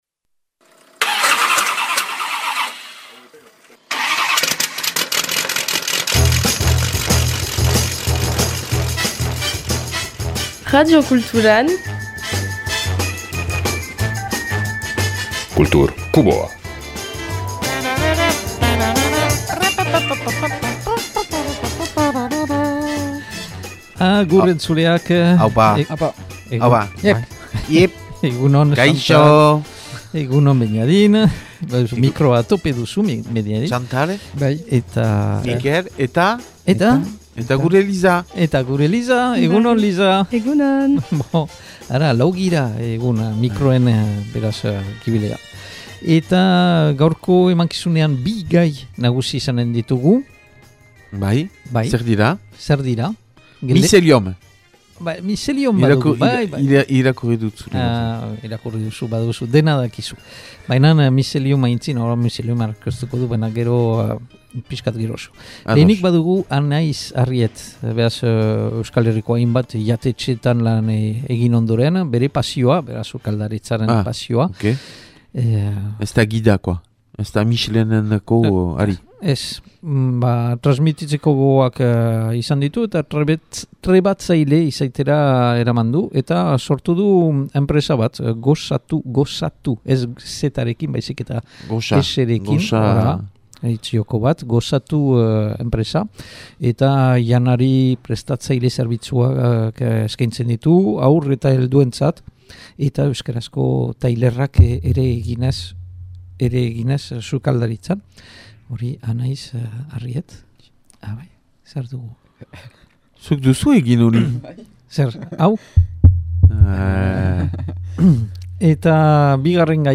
Gaurko bigarren erreportaian Mizelium taldea deskubritzea proposatzen dautzuegu. Mizelium hipnotikoki dantzan jartzen duen elektro-pop laukotea da.